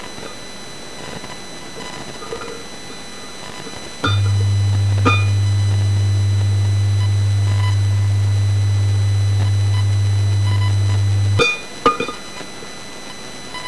Ich habe dann ohne den Umweg Kopfhörer – Smartphonemikrofon das elektrische Ausgangssignal meines Verstärkers in den Line-IN Eingang einer externen Soundcard geführt und dieses „Audiosignal“ mit Audacity aufgezeichnet.
Das sieht schon viel eher nach einem schönen 100 Hz Sinus aus.
Spiele ich diese etwa auf meinem Laptop ab, so höre ich (fast) gar nichts. 100 Hz sind wie schon erwähnt für mein Gehör bereits zu tief. Aber vielleicht hört ja jemand anderer den tiefen Ton 😉
Photoakustischer_Effekt_Audio_Soundcardaufnahme_Laptop_01.wav